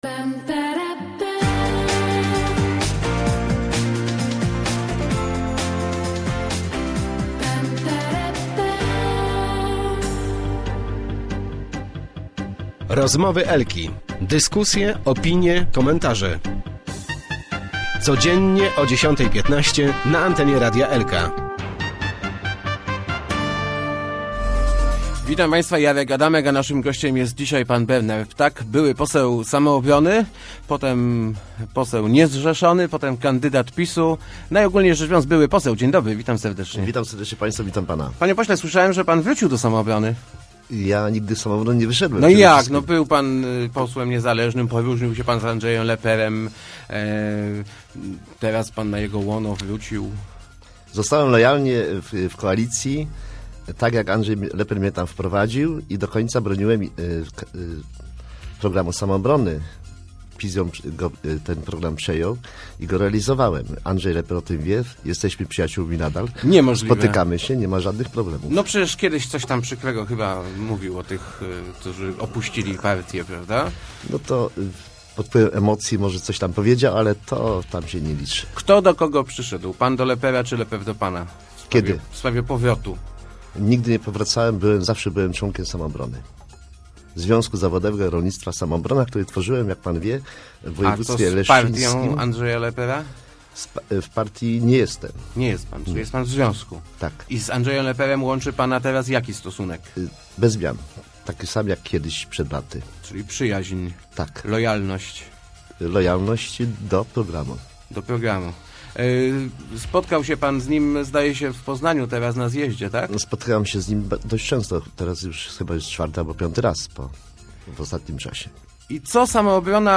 ptak.jpgJest społeczne zapotrzebowanie na Samoobronę - twierdzi były poseł Bernard Ptak, który był dziś gościem Rozmów Elki. Samoobrona szykuje się do wyborów do Parlamentu Europejskiego.